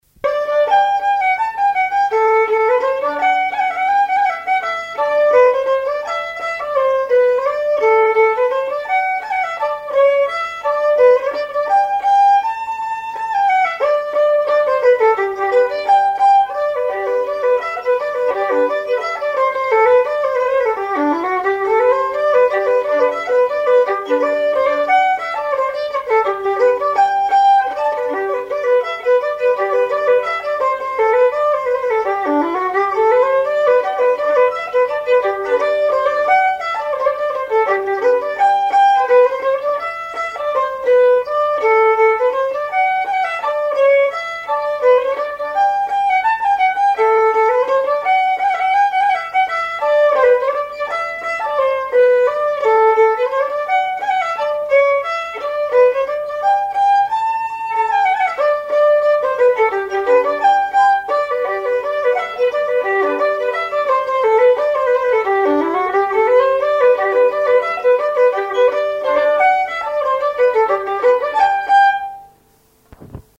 Mémoires et Patrimoines vivants - RaddO est une base de données d'archives iconographiques et sonores.
danse : valse
Auto-enregistrement
Pièce musicale inédite